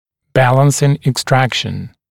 [‘bælənsɪŋ ɪk’strækʃn] [ek-][‘бэлэсин ик’стрэкшн] [эк-]уравновешивающее удаление (удаление того же или соседнего зуба на противоположное стороне зубной дуги)